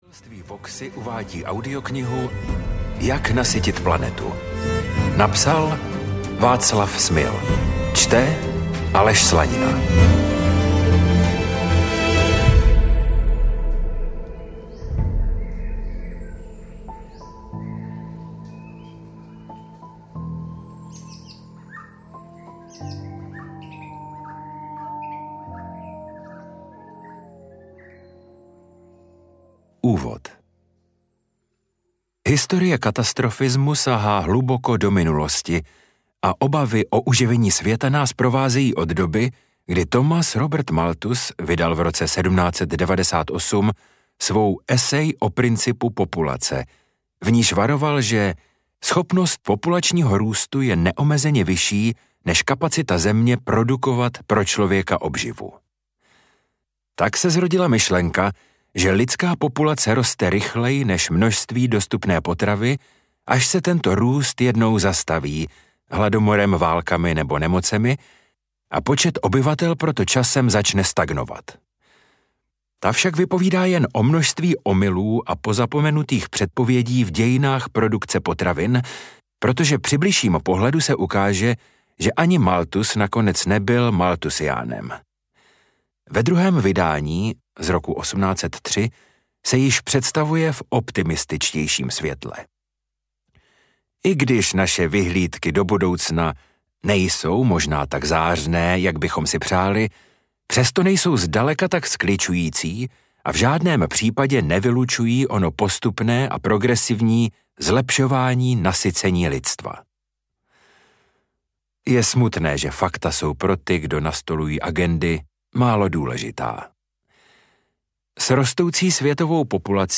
Ukázka z knihy
jak-nasytit-planetu-audiokniha